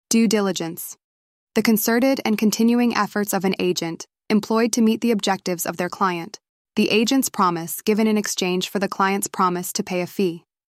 Listen to the terms you’ll need to remember most with an audio reading of definitions while you think through them.